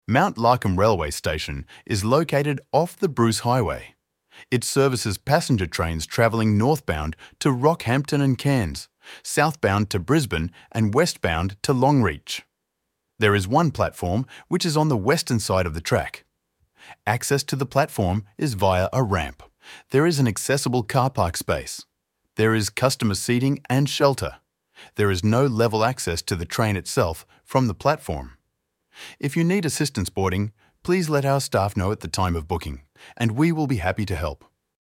Audio description
Mount Larcom railway station.mp3